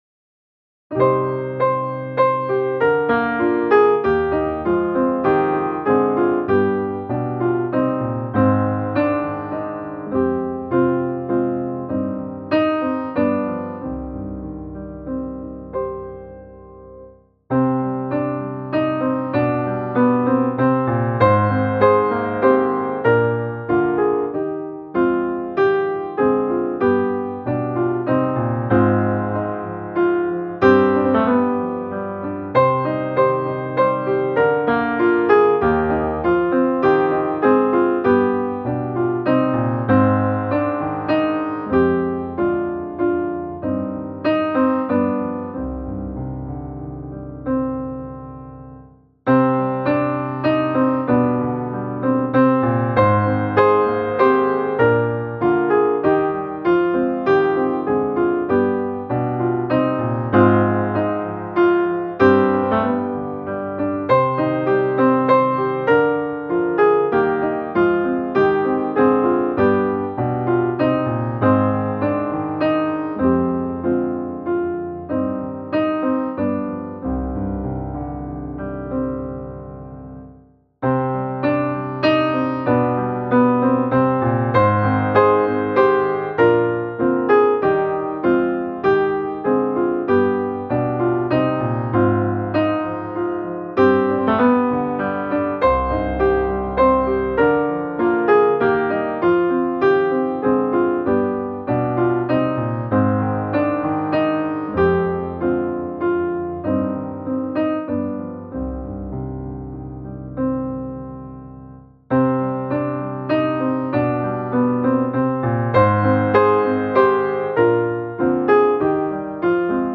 Jesus, det skönaste största och renaste - musikbakgrund